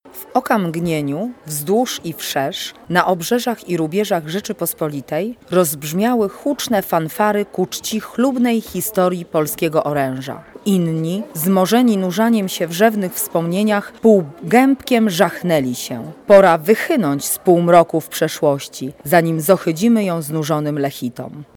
Fragment ubiegłorocznego dyktanda